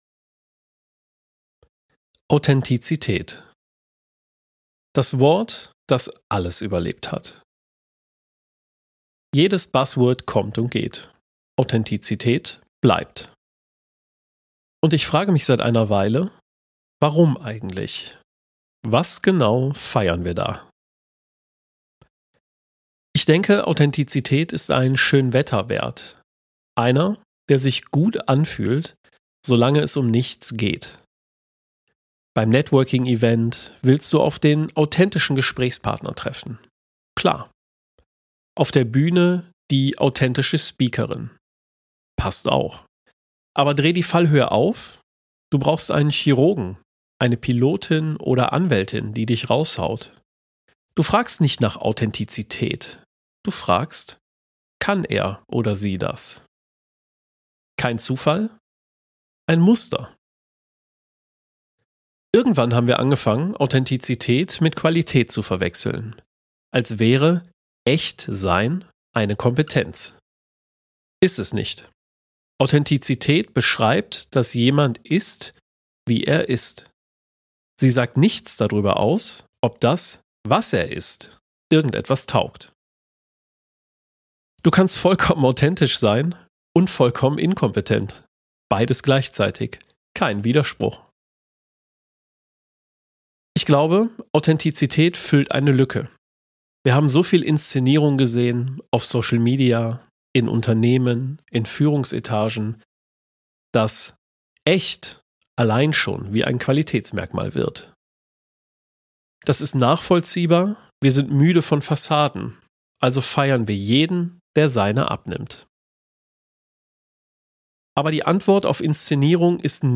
Kein Jingle, kein Small Talk.